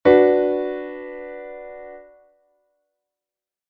Que tipo de acorde estás a escoitar?